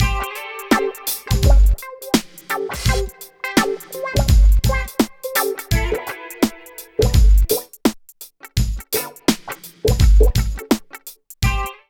137 LOOP  -R.wav